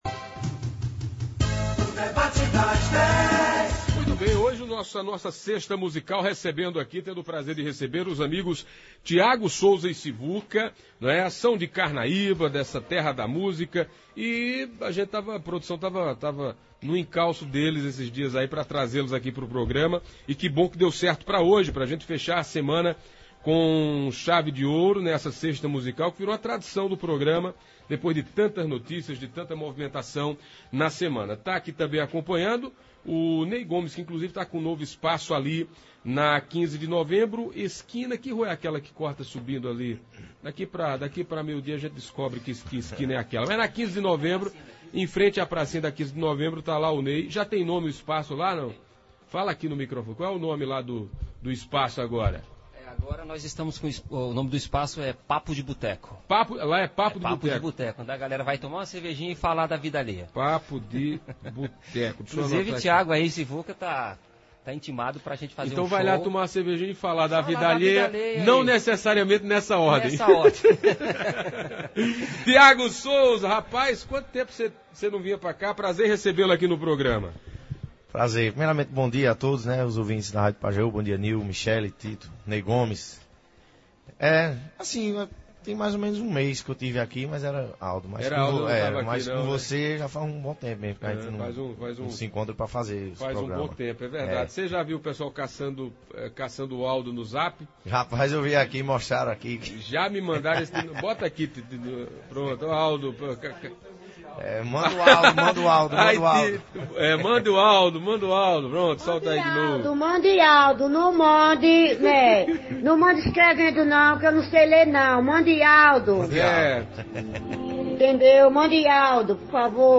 os músicos carnaibanos
cantor